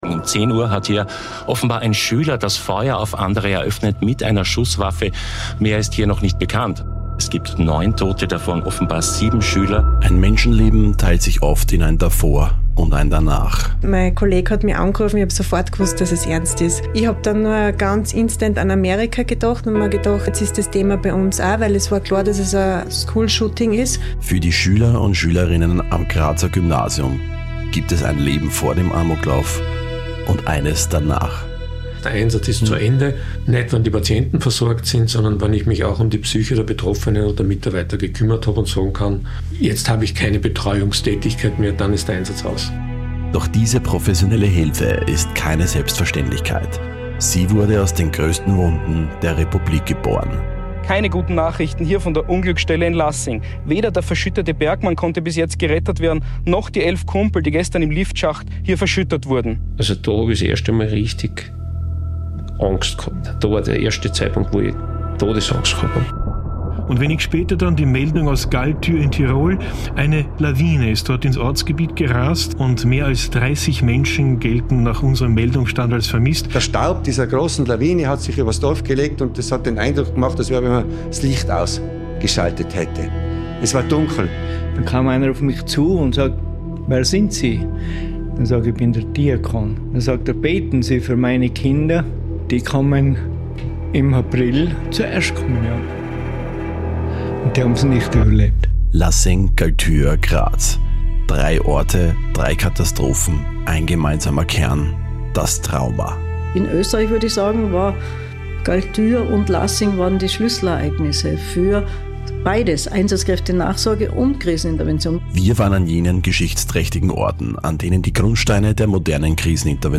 Die Reportage vom Einsatz für Menschen